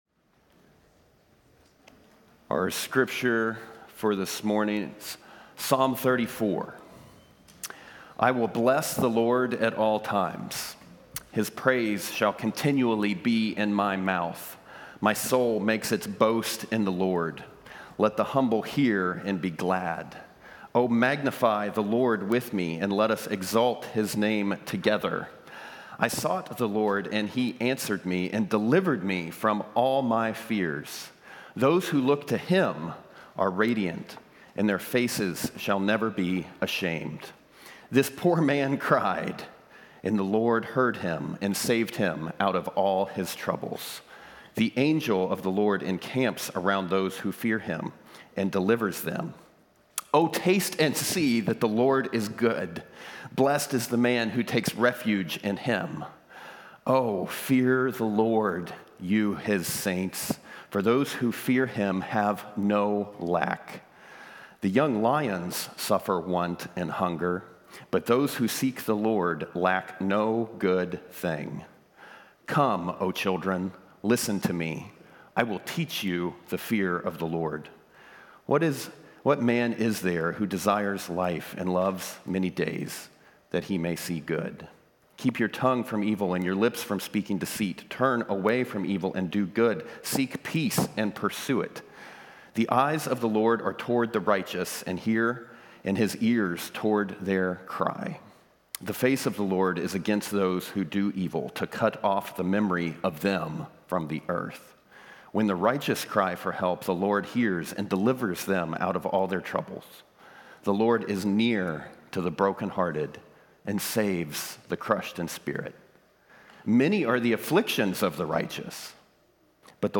A message from the series "This is My Story." This summer, we’re hearing from our elders and various staff about how God has worked in their lives in similar ways to how He worked in the lives of people throughout biblical history.